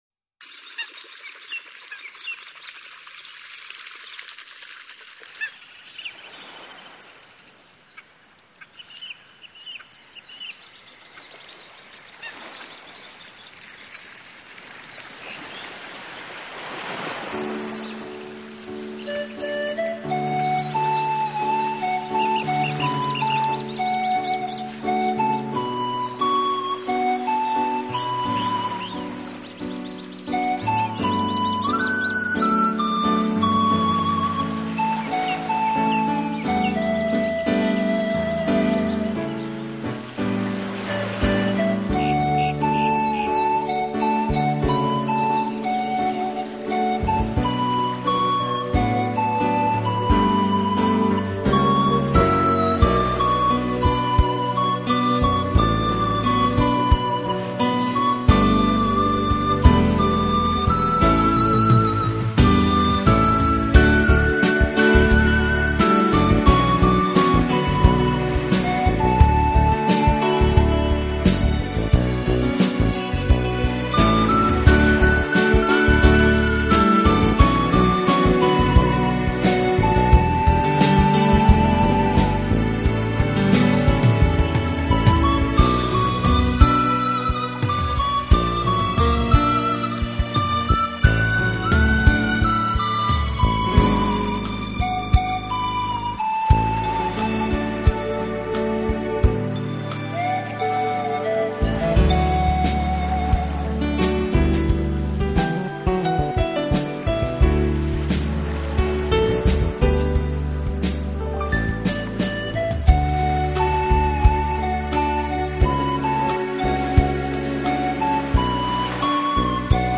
排箫的声音，清亮中含着暗哑，悠扬中带着迷朦，空旷中透出冥思，深情
用排箫演绎西洋名曲，更充分地表现了含蓄、柔美的东方气质，